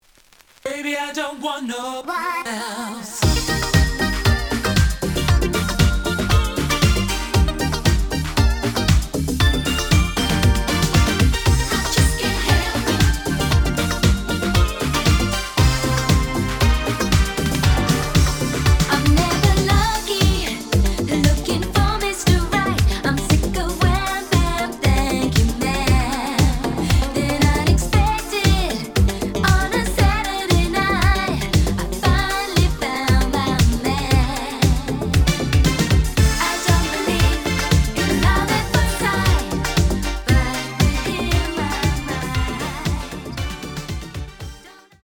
試聴は実際のレコードから録音しています。
●Format: 7 inch
●Genre: Soul, 80's / 90's Soul